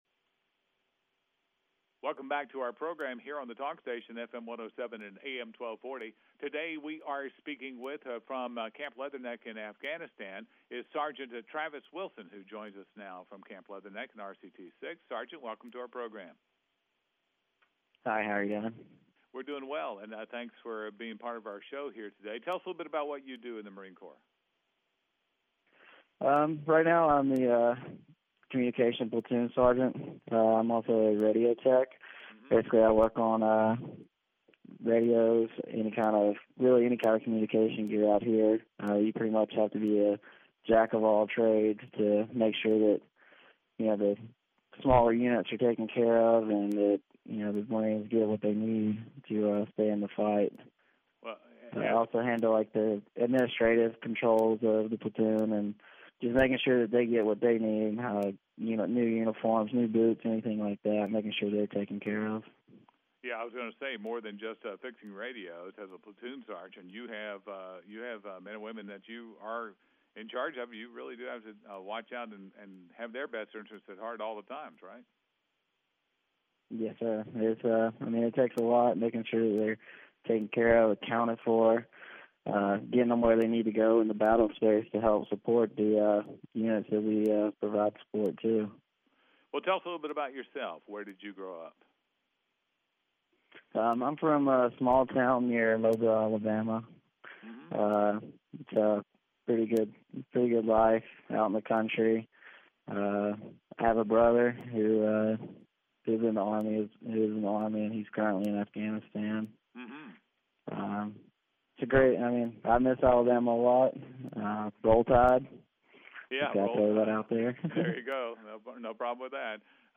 talks to a WTKF-FM reporter about his duties as a communications platoon sergeant and a radio technician deployed to Afghanistan.